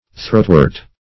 Throatwort \Throat"wort`\, n. (Bot.)